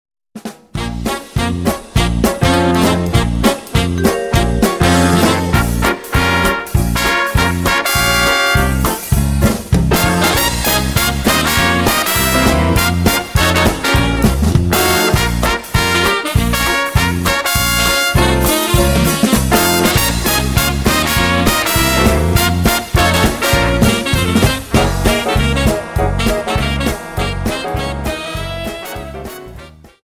Dance: Quickstep